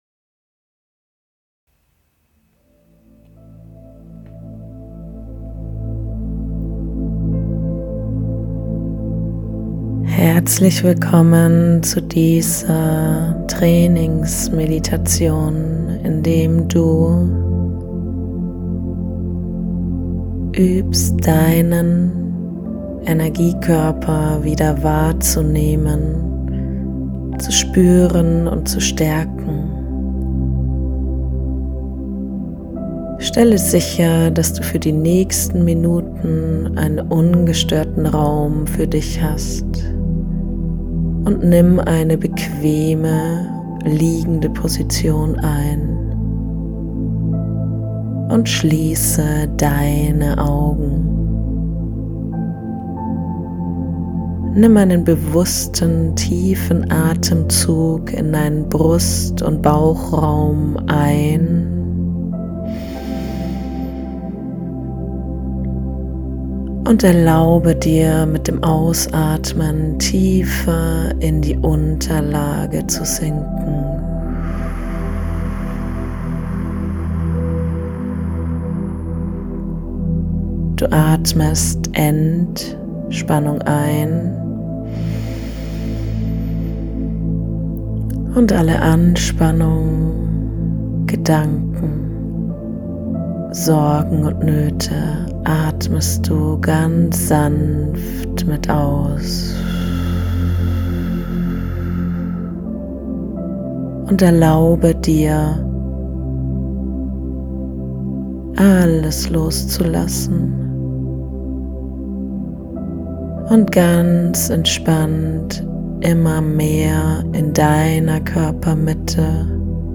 Meditation – Dein Energiekörper Setze oder lege dich für die Meditation bequem hin.